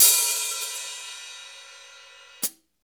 CYM X14 HA0C.wav